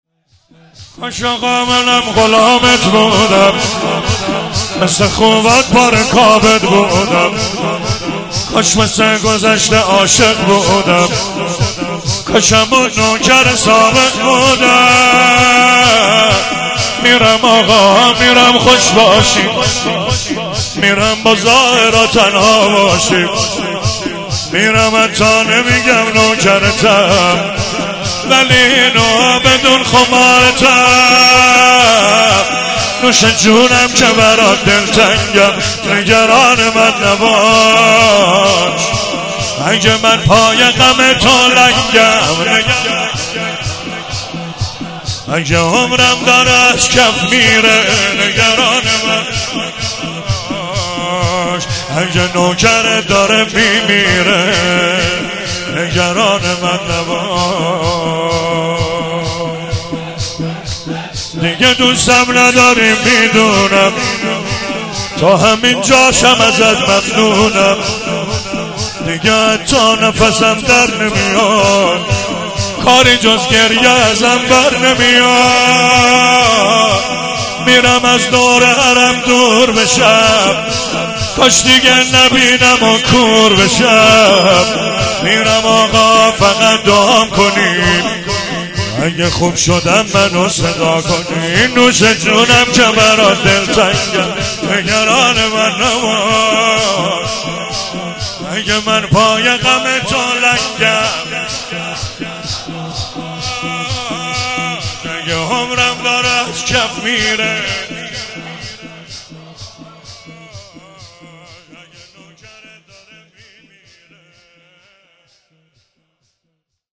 چهاراه شهید شیرودی حسینیه حضرت زینب (سلام الله علیها)
شور